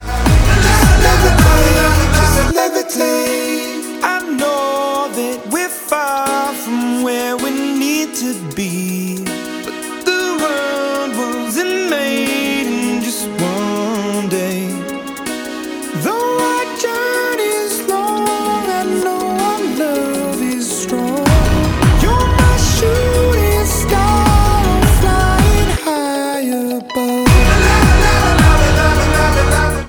Genre: Alternative